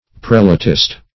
Prelatist \Prel"a*tist\, n.